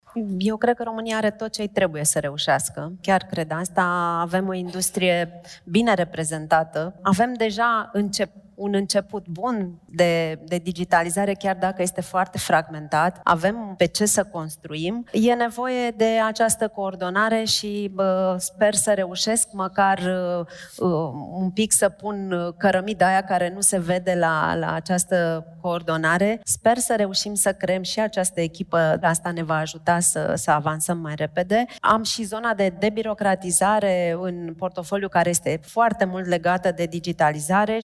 Vicepremierul Oana Gheorghiu, în cadrul „Summitului pentru guvernanță digitală”: „Avem deja un început bun de digitalizare, chiar dacă este foarte fragmentat”